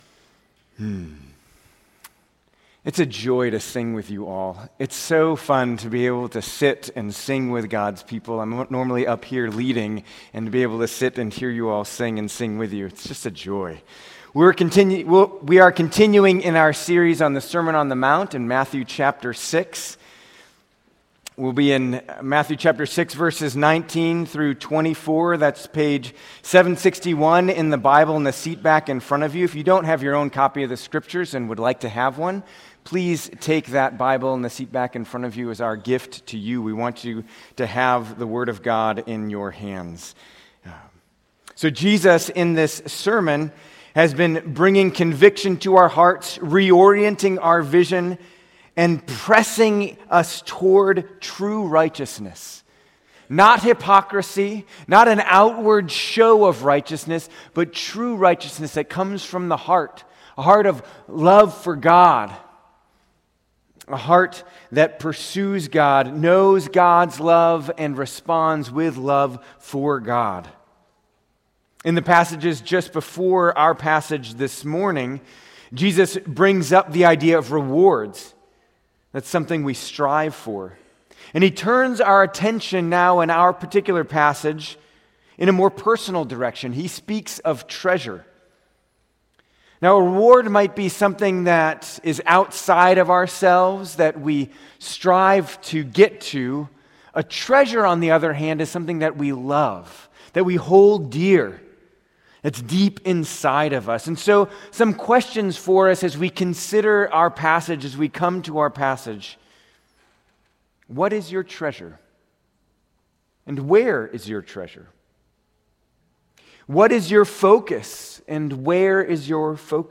In this wonderful sermon, Jesus continues to declare to us the true nature of citizens of the kingdom of God.